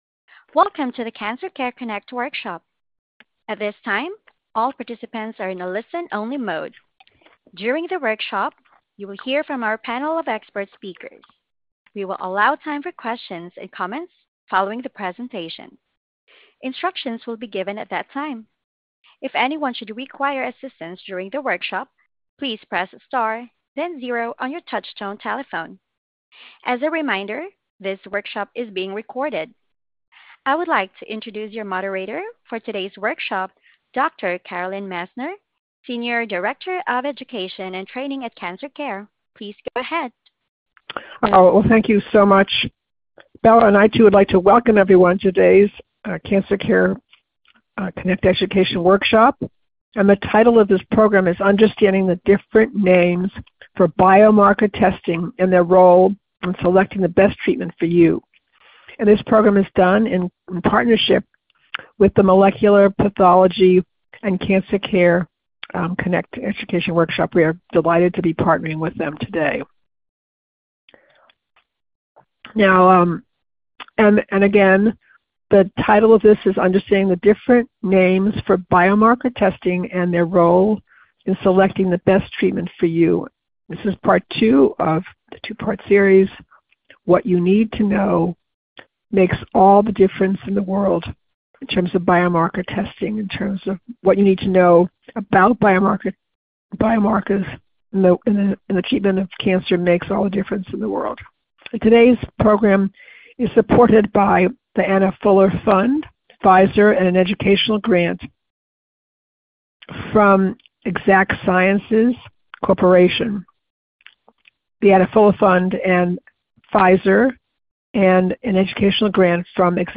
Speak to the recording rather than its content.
This workshop was originally recorded on March 10, 2025.